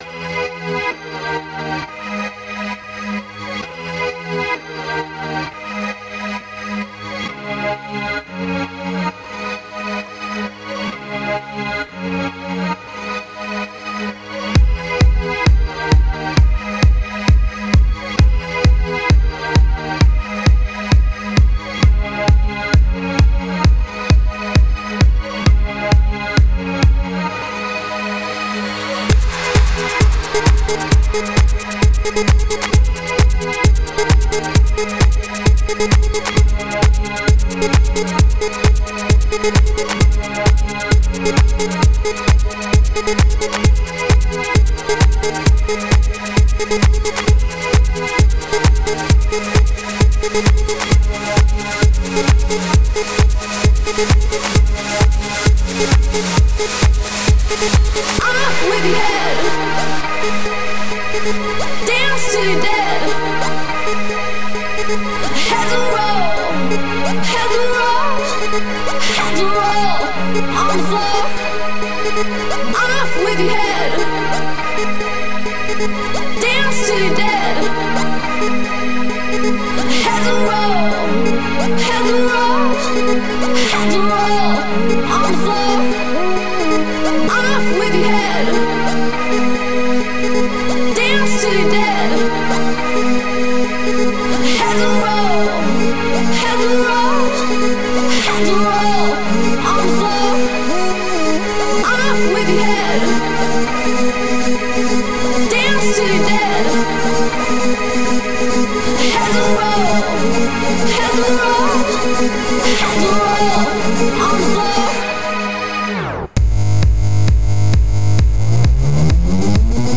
Oh yeah, and there was one rule: no dub-step.